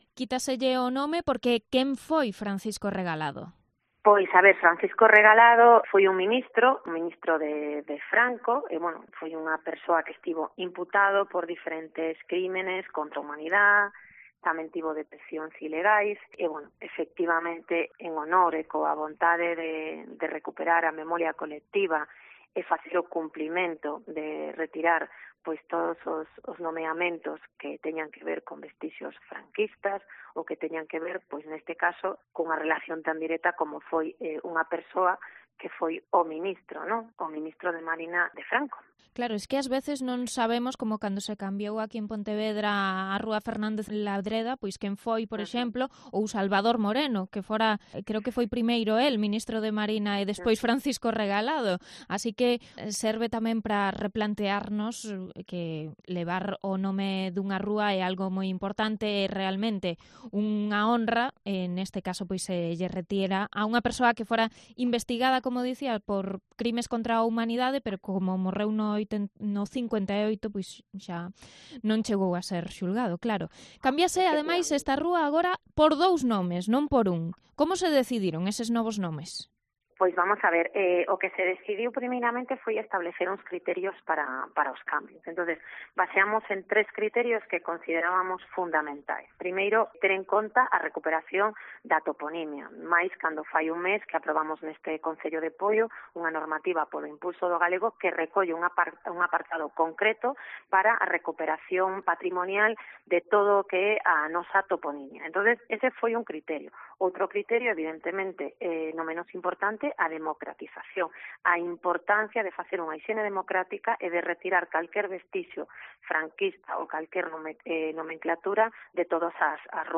Entrevista a la concejala de Memoria Histórica de Poio sobre la avenida Francisco Regalado